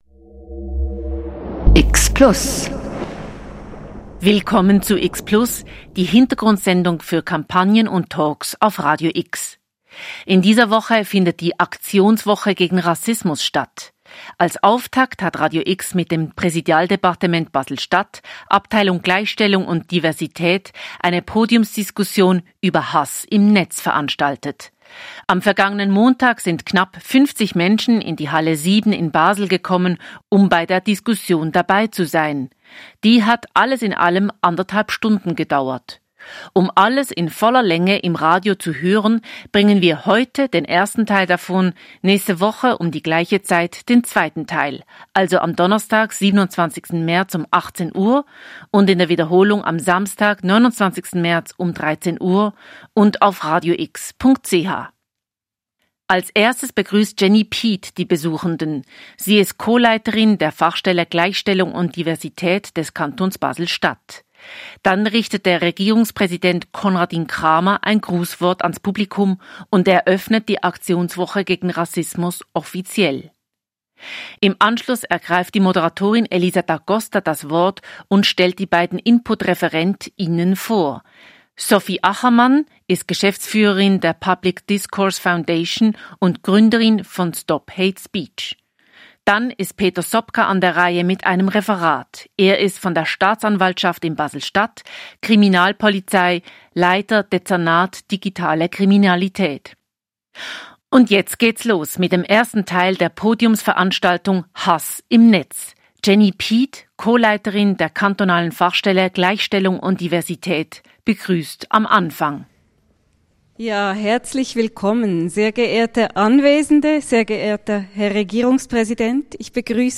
Podiumsdiskussion Hass im Netz ~ Spezialthemen Podcast
Beschreibung vor 1 Jahr Die Aktionswoche gegen Rassismus 2025 ist mit der Podiumsveranstaltung offiziell eröffnet: Am Montag, 17. März um 17.00h waren fünf Gesprächspartner:innen in der Halle 7 in Basel miteinander im regen Austausch. Radio X bringt die anderthalb Stunden dauernde Diskussion als X-Plus-Sendungen in zwei Teilen.